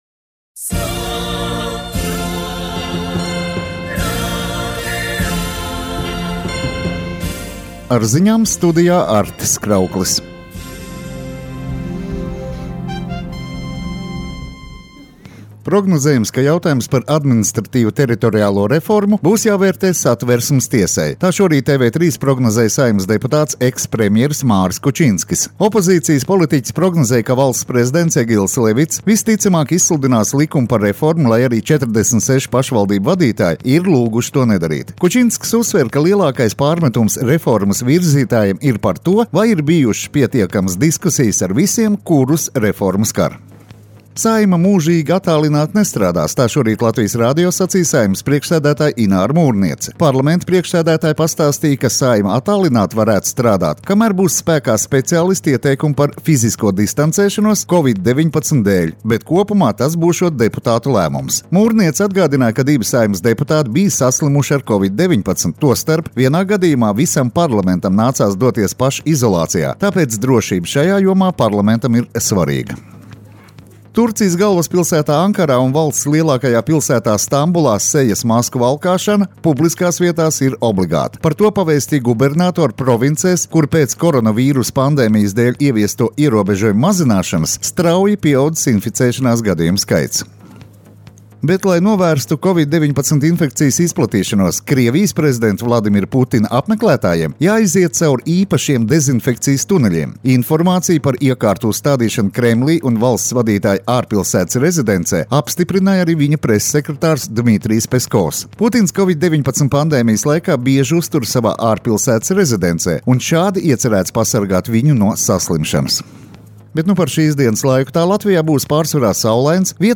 Radio Skonto ziņas Rīta programmā 18.06.